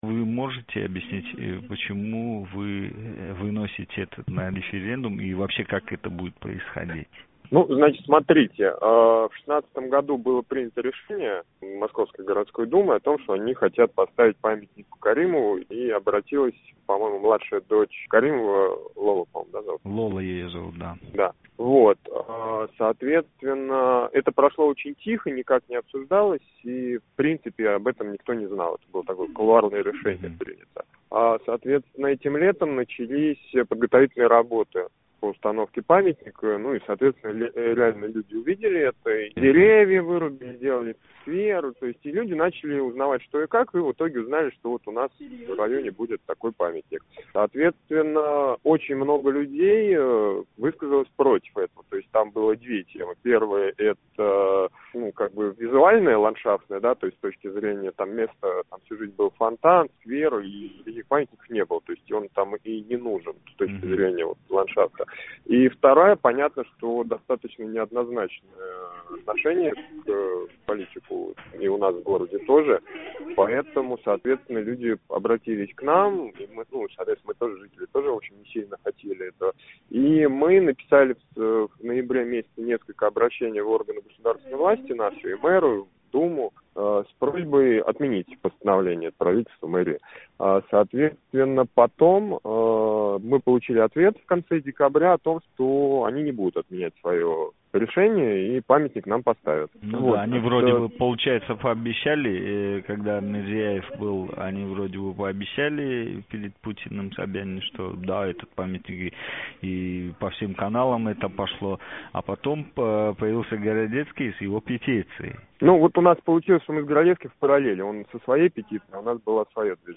(Якиманка округи раҳбари Андрей Моревнинг референдум борасидаги нутқи)